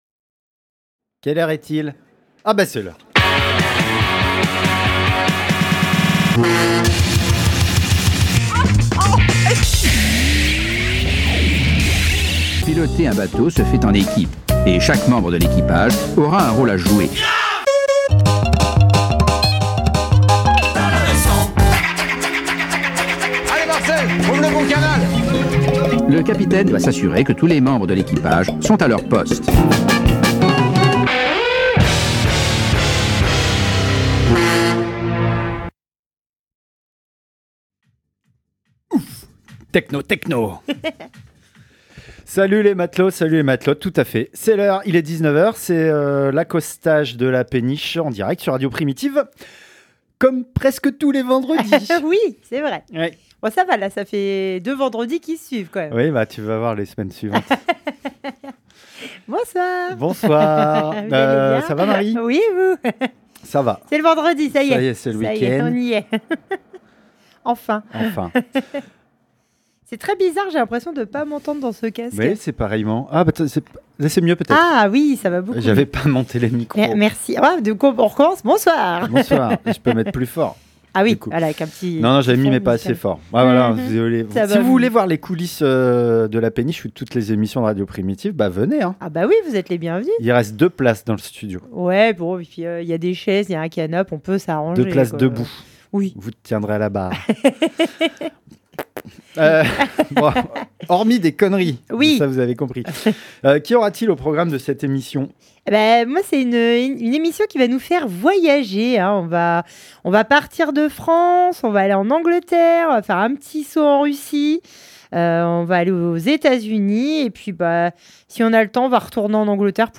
🎧 Péniche 15x04 - Péniche radio show